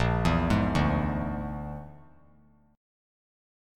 A#7b5 Chord
Listen to A#7b5 strummed